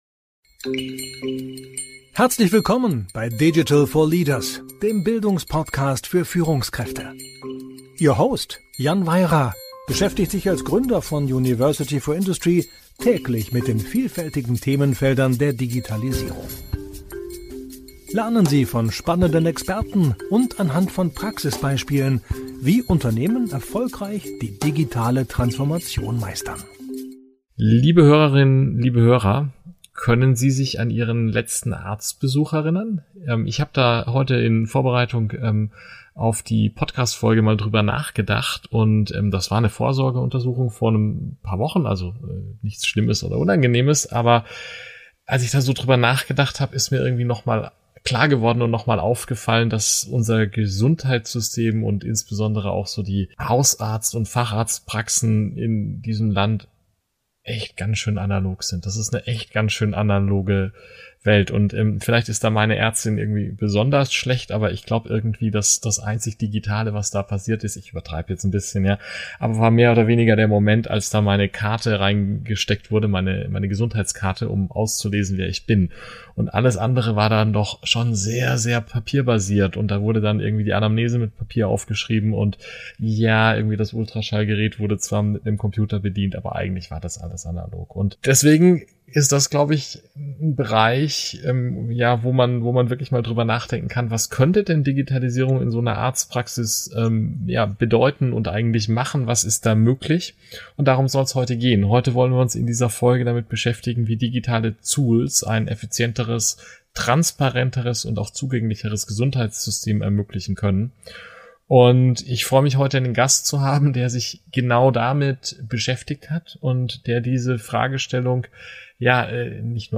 Digital4Leaders - der Bildungspodcast für Führungskräfte